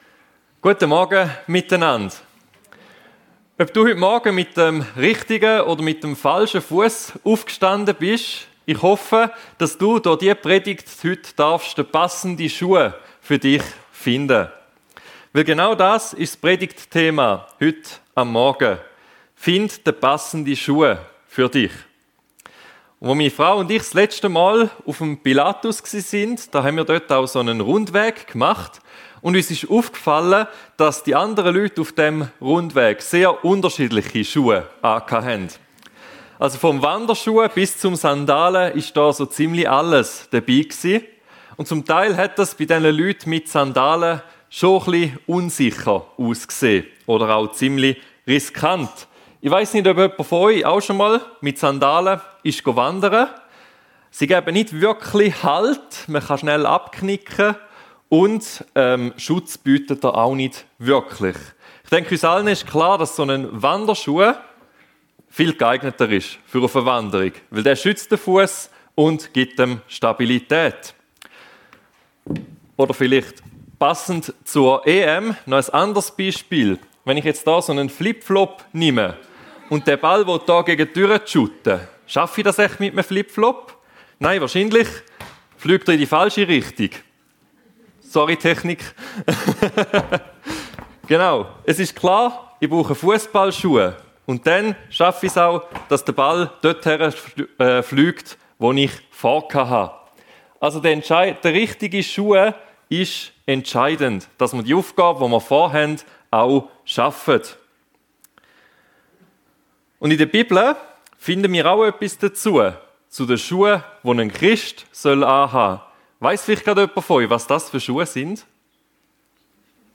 Finde den passenden Schuh für dich ~ FEG Sumiswald - Predigten Podcast